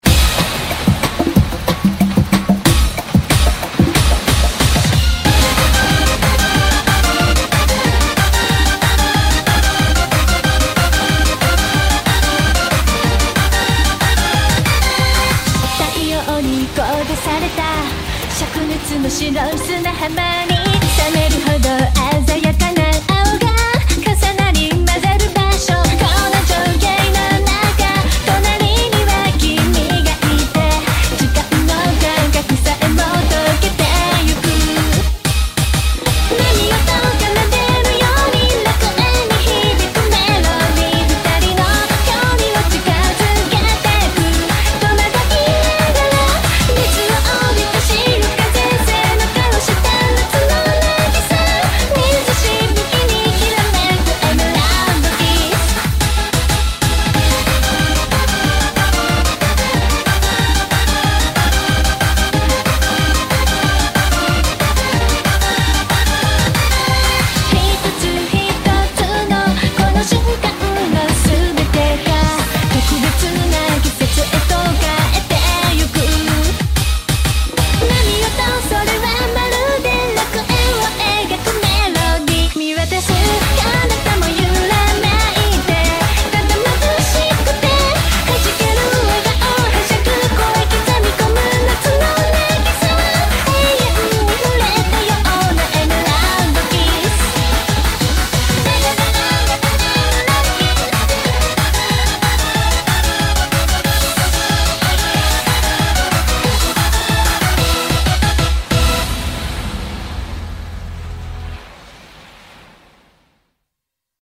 BPM185
Audio QualityCut From Video